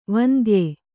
♪ vandye